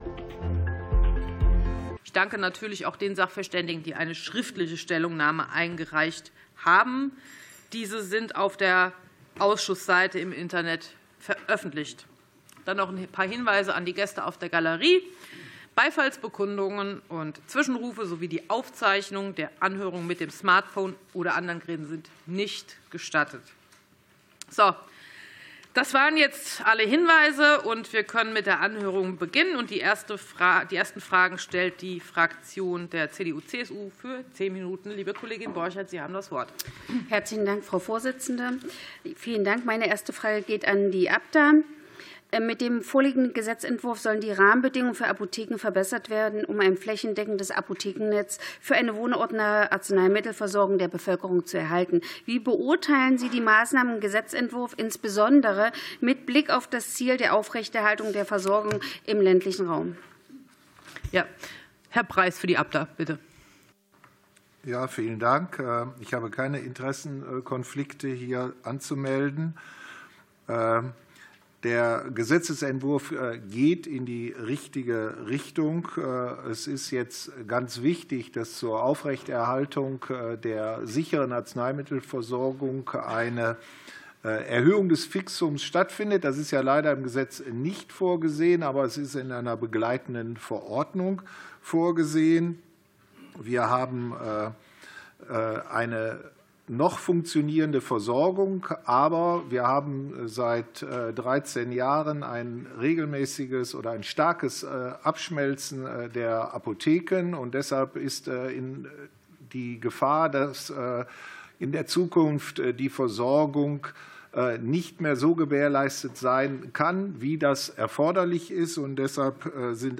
Anhörung des Ausschusses für Gesundheit